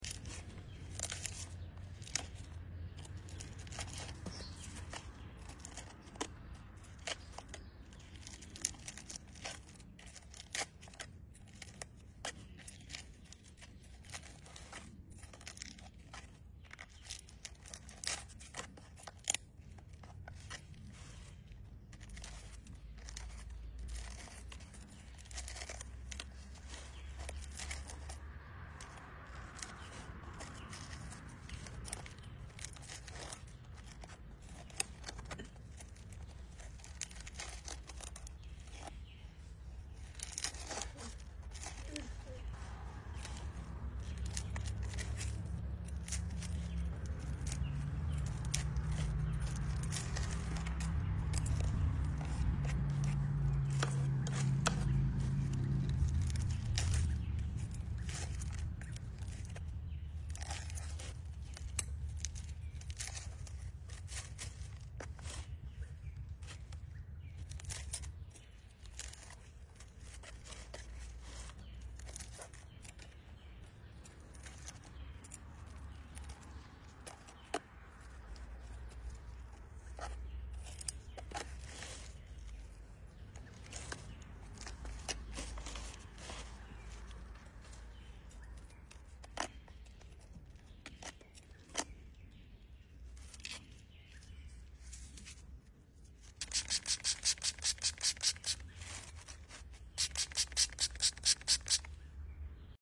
Settle in for an extended ASMR session as I peel away layers of dry succulent leaves. Enjoy every satisfying snap and crisp sound for pure relaxation and plant care bliss.